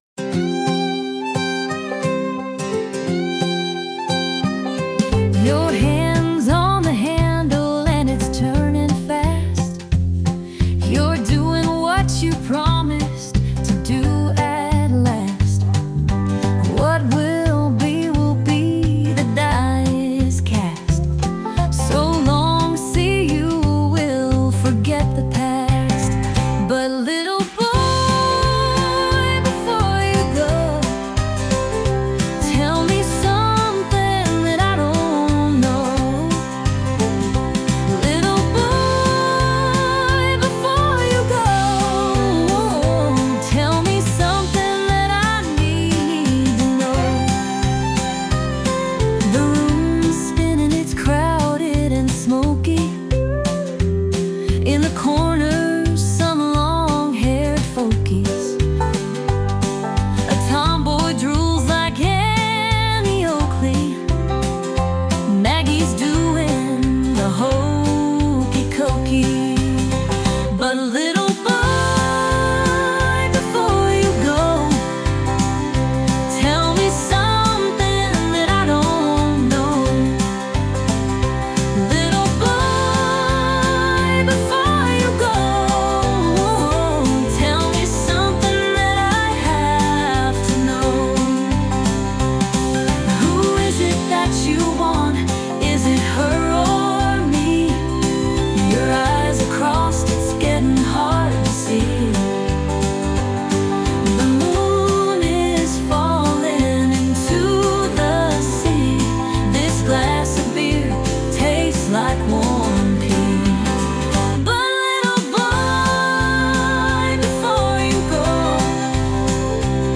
female led Country